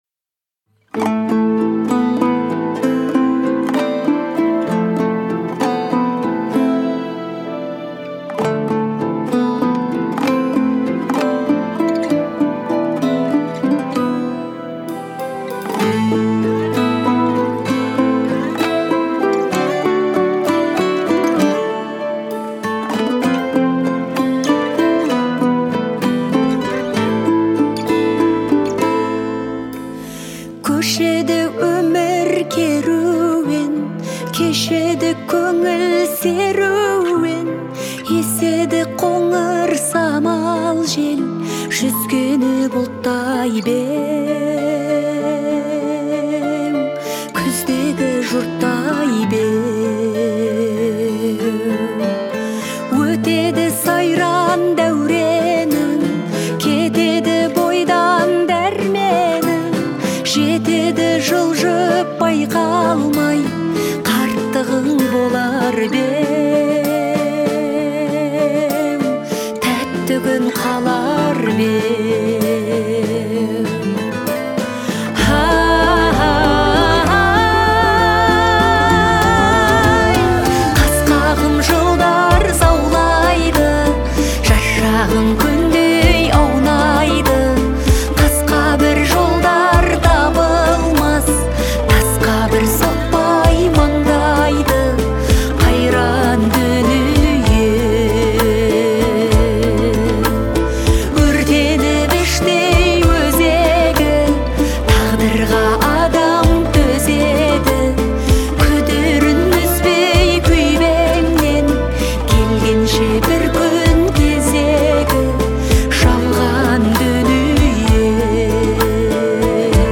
казахской певицы